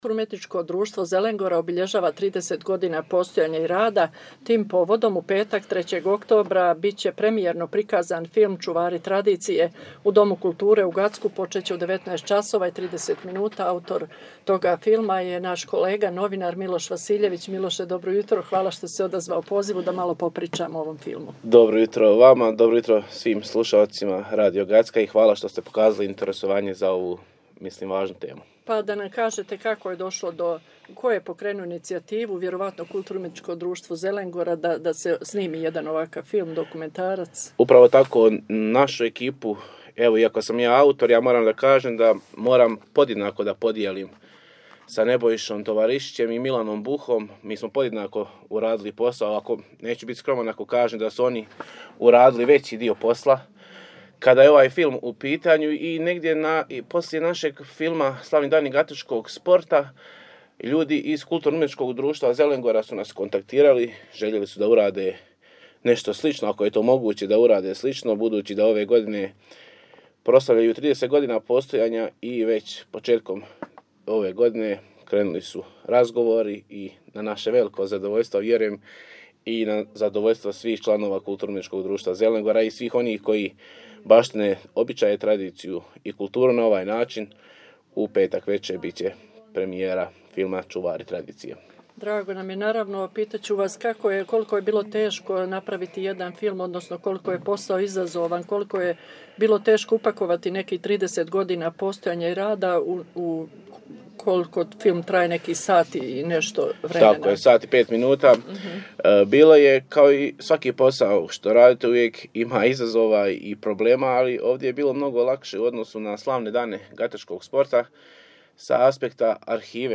Опширније у разговору